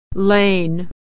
Vowel Sounds /e, ey/ - Practice - Lie-lay - Authentic American Pronunciation
lain /ey/